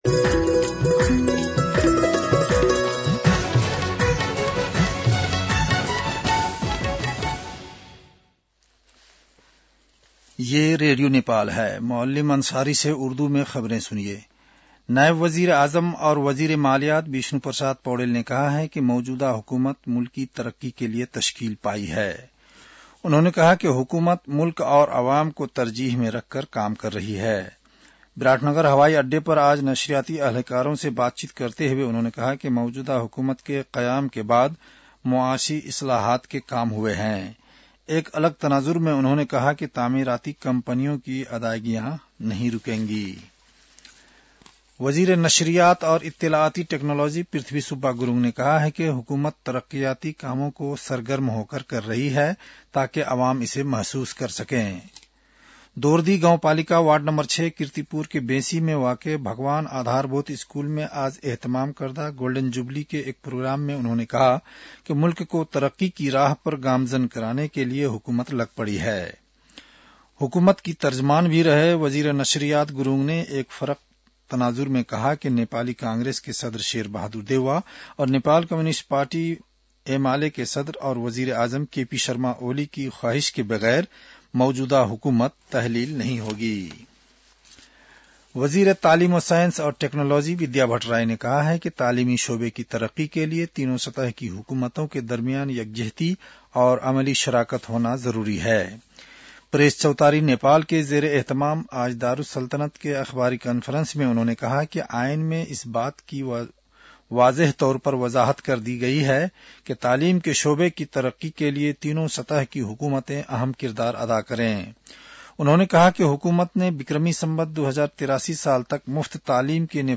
उर्दु भाषामा समाचार : १३ माघ , २०८१